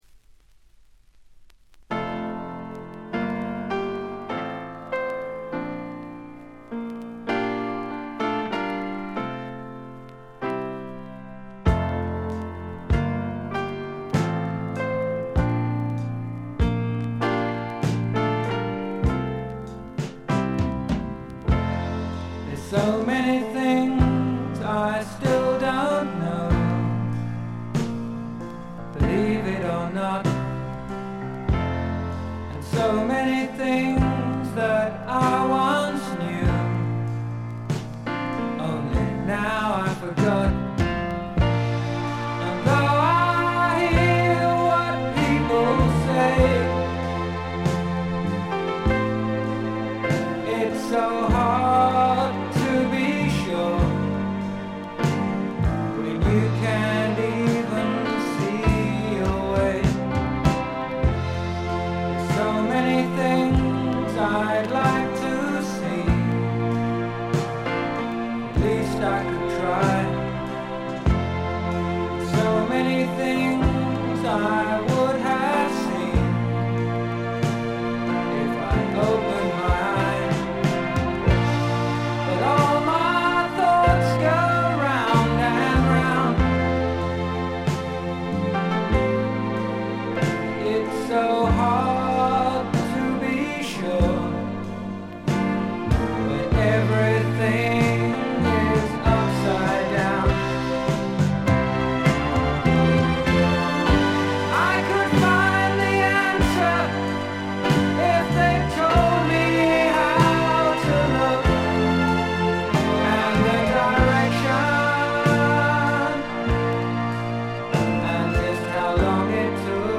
静音部で少軽いバックグラウンドノイズ程度。
試聴曲は現品からの取り込み音源です。